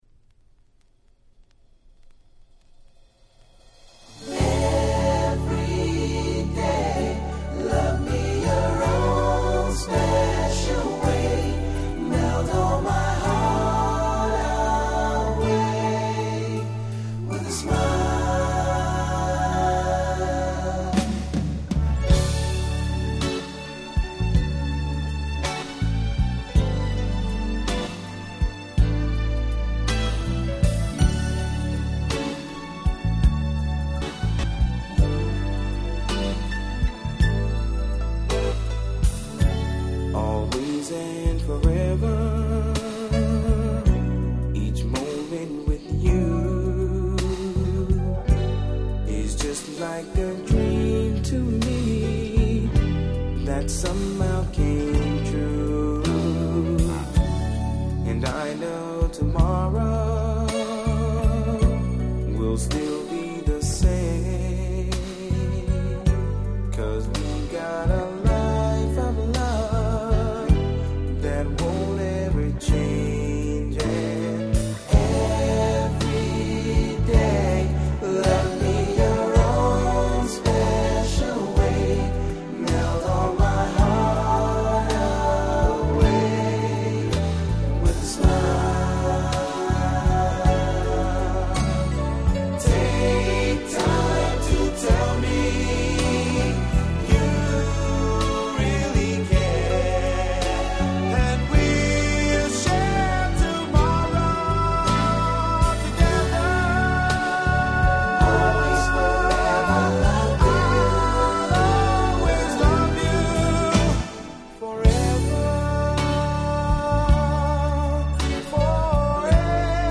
90' Smash Hit Slow Jam !!
若干のイナタさもありつつ、胸にグッと来る素晴らしい1曲です。